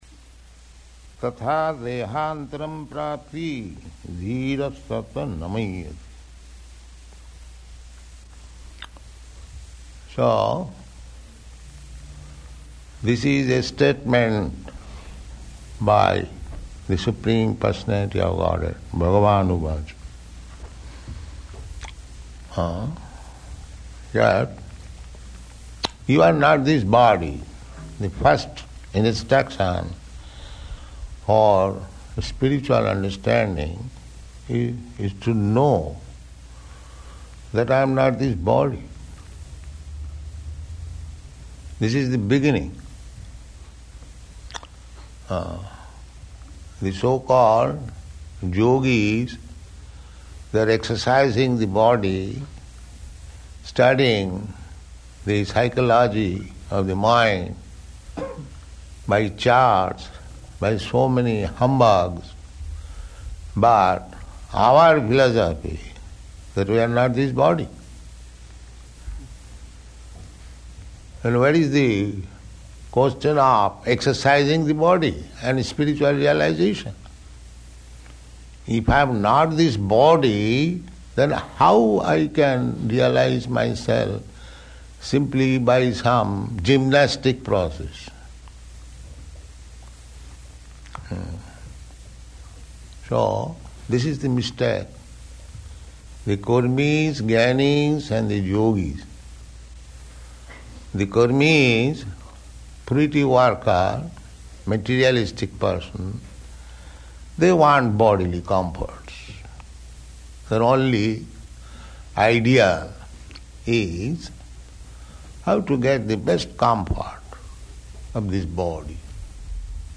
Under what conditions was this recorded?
Location: New York